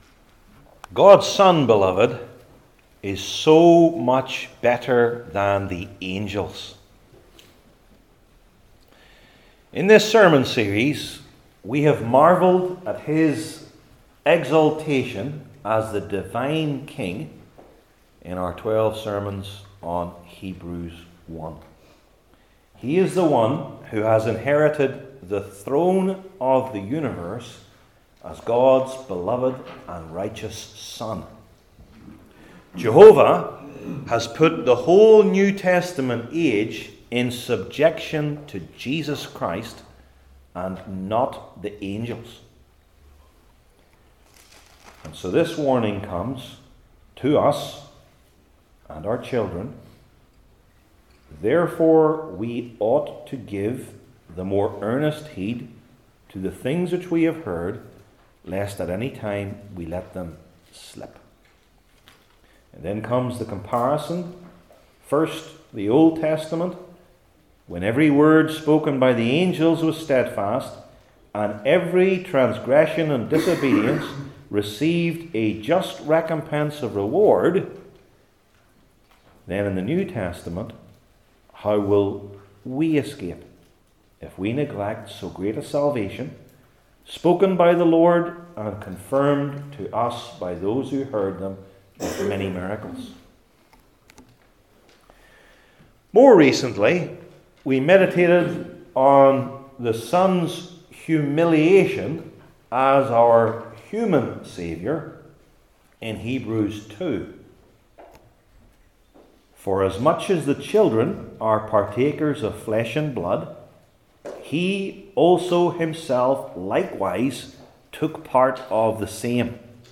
Hebrews 2:16-18 Service Type: New Testament Sermon Series I. The Nature He Assumed II.